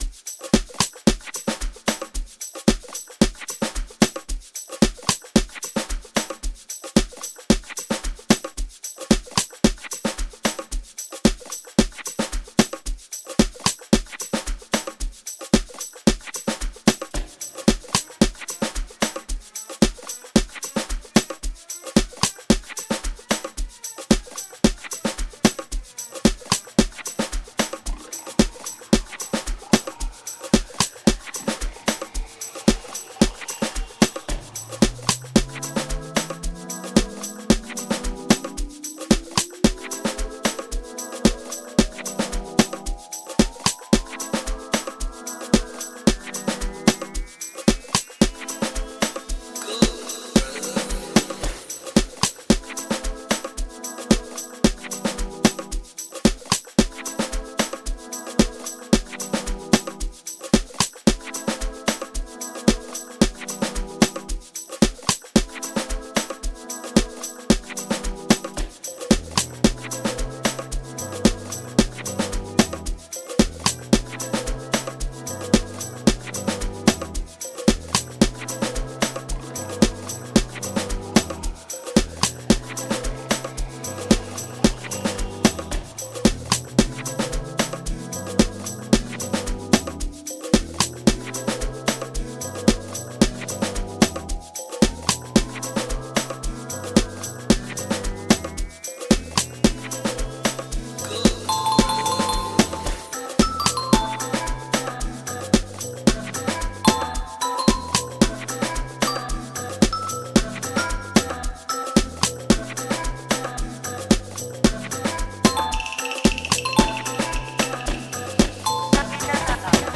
07:30 Genre : Amapiano Size